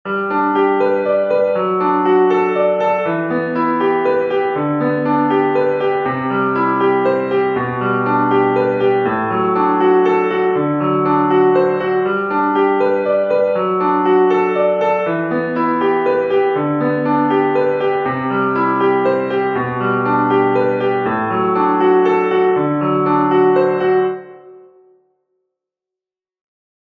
So sieht jetzt meine Akkordfolge aus – genau wie ich wollte, von G bis D7 und mit gesamthaft 8 Akkorden:
Also dachte ich: Lass uns noch einen Ton anhängen und die Akkordfolge im 6/8-Takt spielen:
G-Dur Arpeggio Begleitmuster Klavier
Dieses Muster wende ich jetzt für alle anderen Akkorde an: Ich nehme immer als erstes den jeweiligen Basston in der linken Hand, dann einen anderen Ton vom Akkord, der etwa in Quint-Abstand dazu ist und dann spiele ich mit der rechten Hand den Dreiklang hoch und bis zur Hälfte runter.
Akkordfolge G-Dur Klavier Begleitmuster
akkordfolge-auf-g.mp3